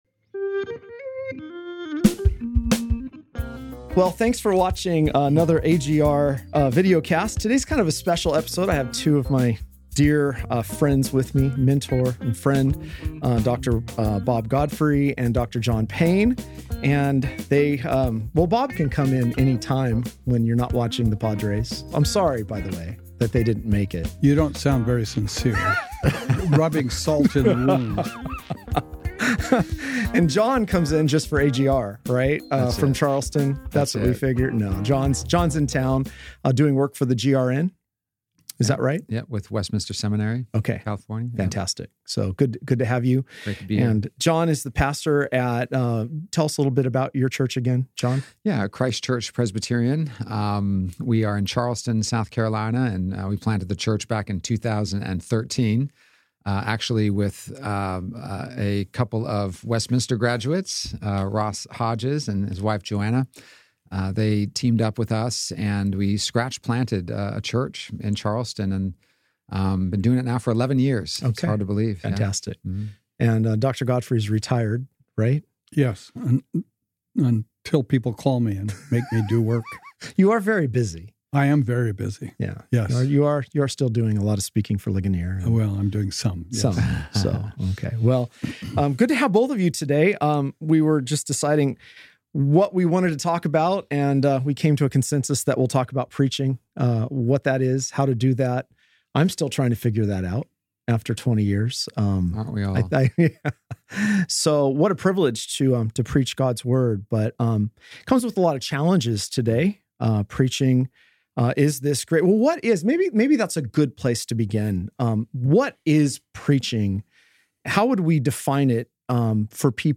in the AGR studio. The topic on the table is preaching.